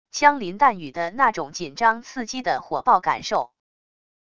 枪林弹雨的那种紧张刺激的火爆感受wav音频